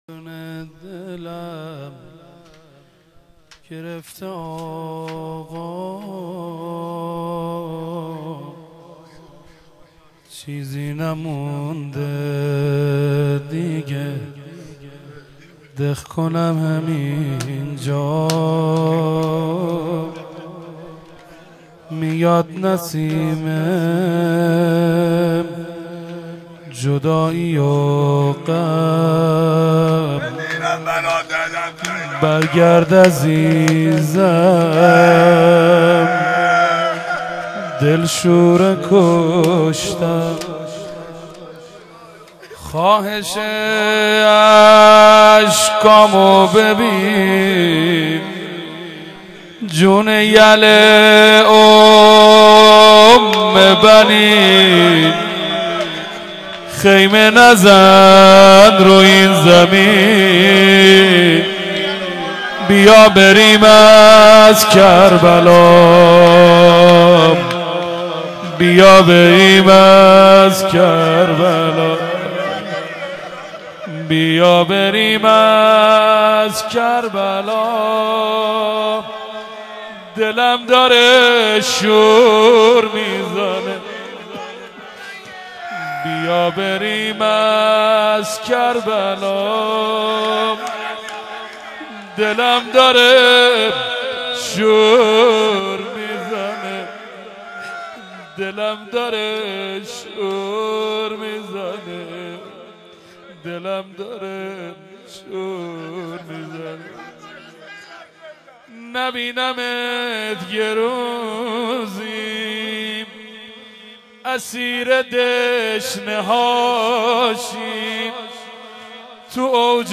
فایل های مدح وسینه زنی
8-روضه پایانی